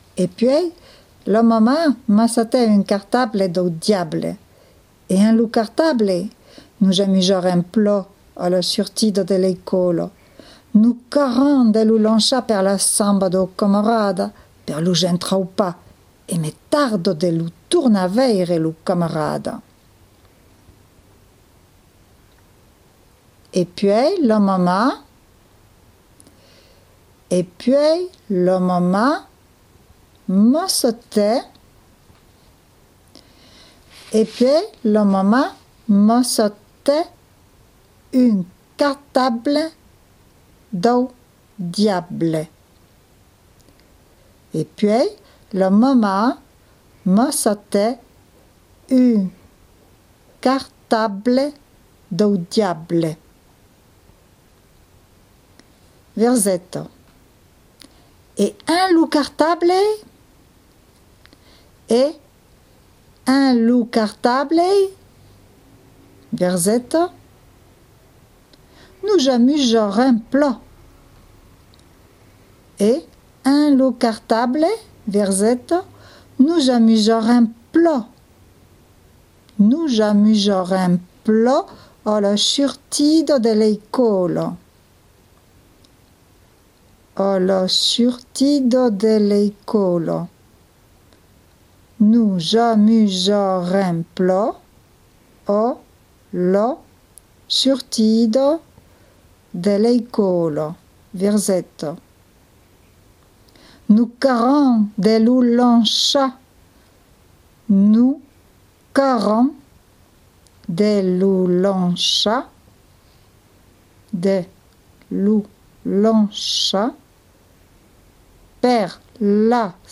La Dictada dau Pitit Nicolau #2, qu’es ‘na dictada bien mai corta per tots los que voldrian s’eissaiar chas ilhs a l’escritura de l’occitan :
2/ Dictada
3/ Lectura finala
Texte legit per